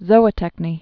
(zōə-tĕknē)